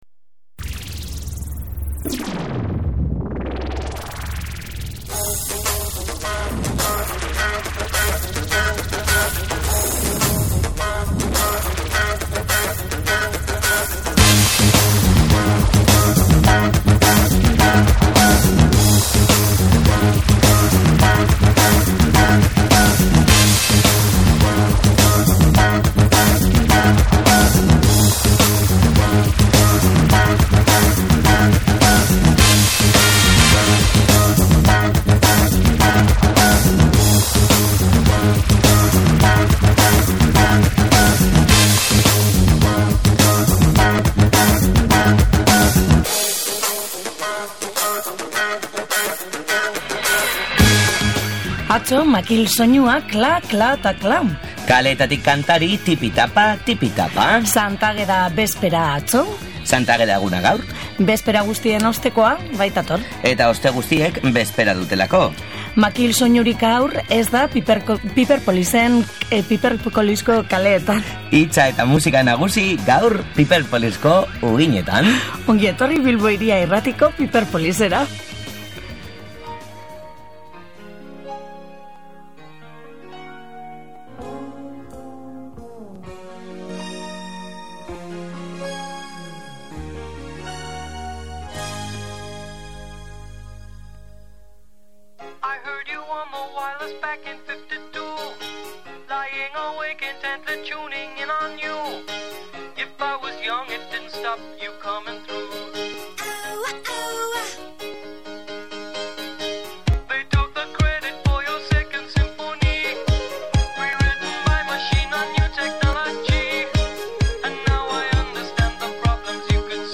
hamabi kanta akustikoekin osatutako diskoa, Piperpolisen entzun gai izan dira.